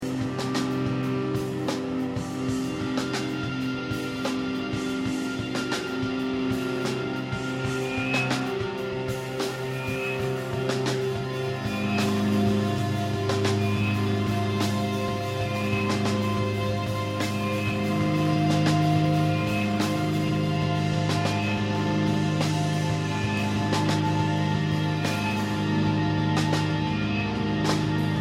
both of which were recorded live to DAT.